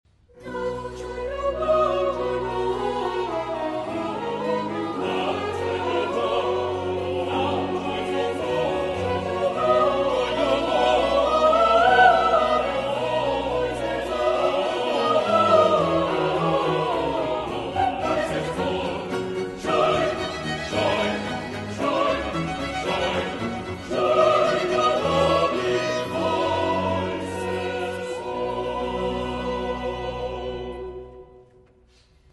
Chorus.